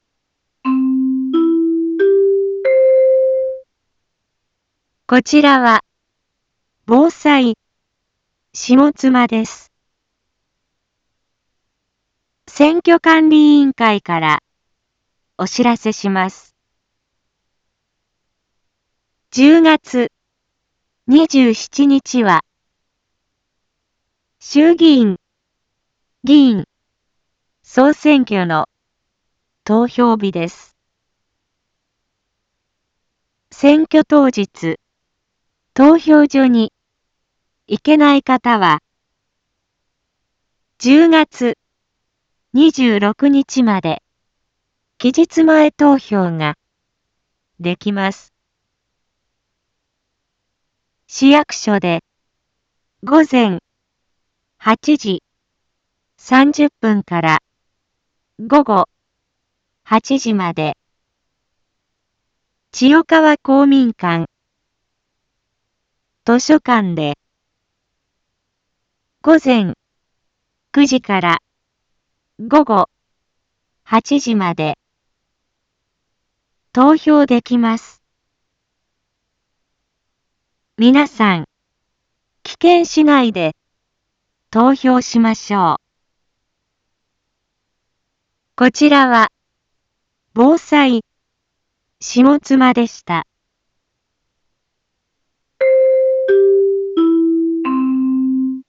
一般放送情報
Back Home 一般放送情報 音声放送 再生 一般放送情報 登録日時：2024-10-25 18:31:44 タイトル：衆議院議員総選挙の啓発（期日前投票期間） インフォメーション：こちらは、ぼうさいしもつまです。